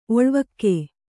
♪ oḷvakke